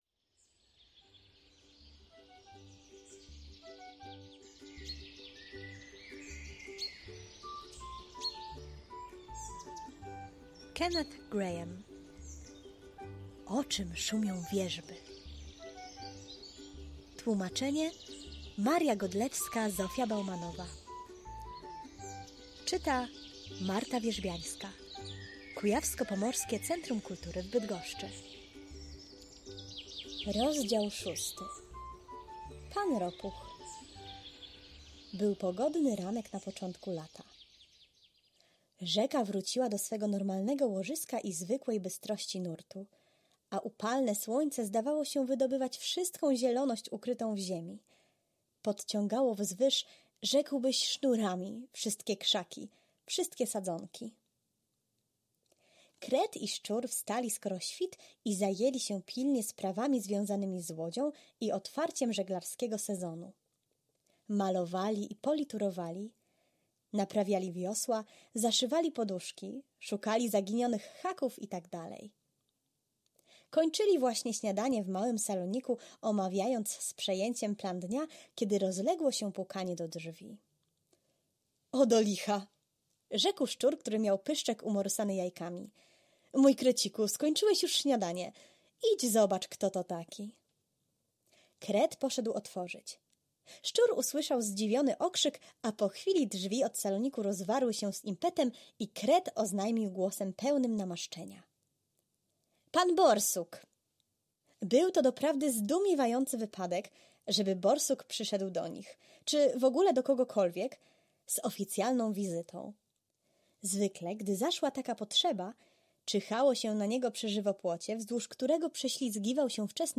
Rozdział VI: „Pan Ropuch” – audiobook w odcinkach online - KPCK
Kogo sąd skazał na dwadzieścia lat więzienia? Zapraszamy do wysłuchania kolejnego rozdziału audiobooka online.